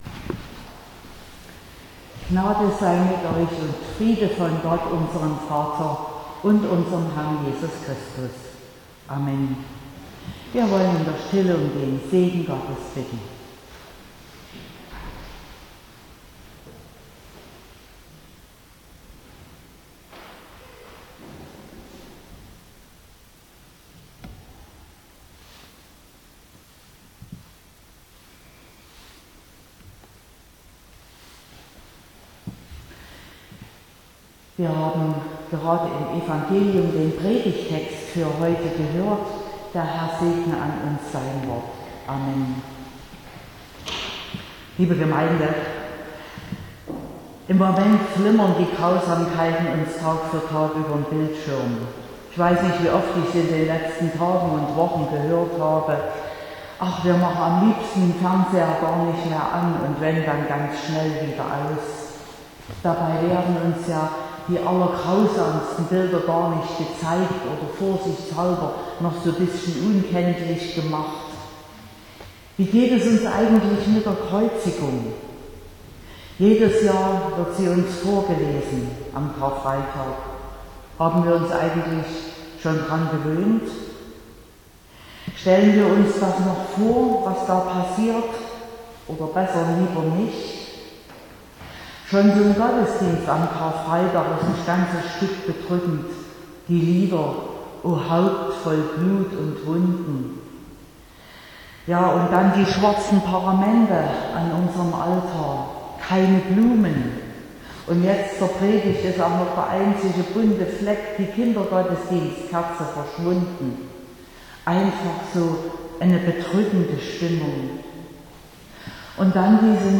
15.04.2022 – Gottesdienst
Predigt (Audio): 2022-04-15_Karfreitag_-_nicht_nur_zuschauen_-_entscheiden.mp3 (20,3 MB)